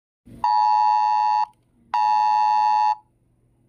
MDRRMC Alert
mdrrmc-alert.mp3